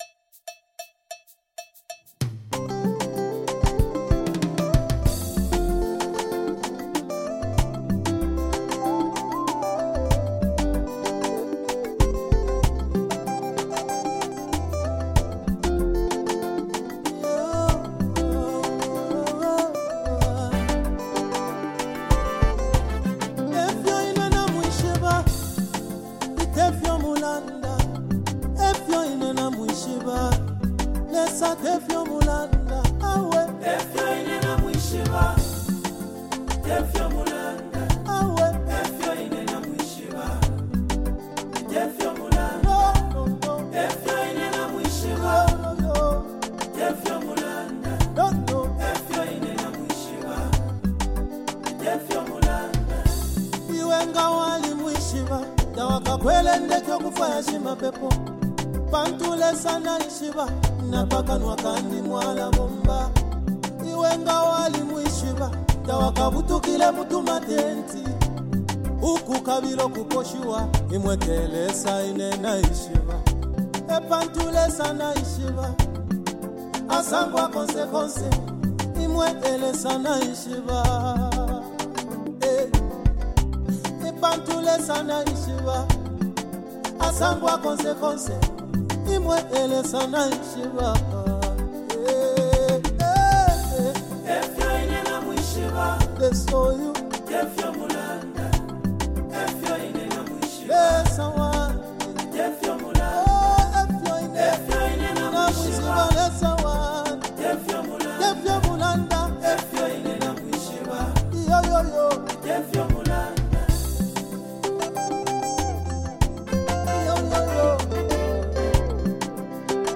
Gospel Music
powerful and soulful gospel song